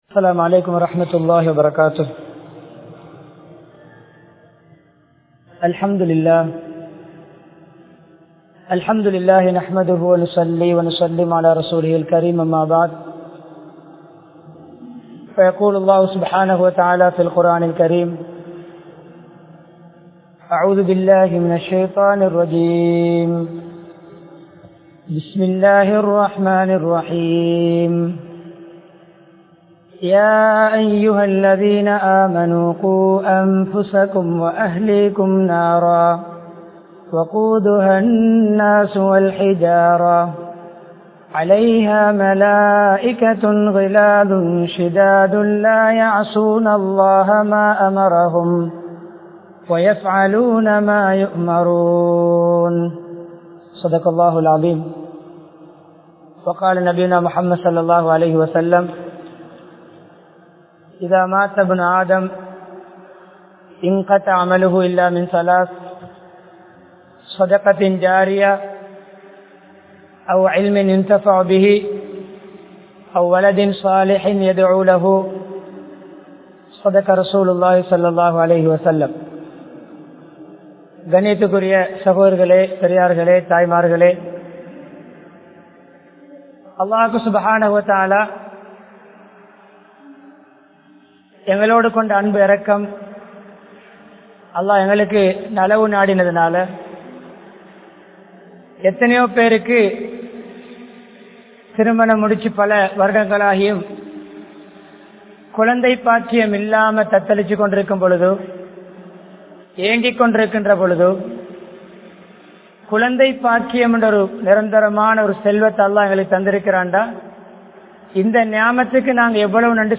Makthab Kalvien Noakkam Enna? (மக்தப் கல்வியின் நோக்கம் என்ன?) | Audio Bayans | All Ceylon Muslim Youth Community | Addalaichenai
Mannar, Uppukkulam, Al Azhar Jumua Masjidh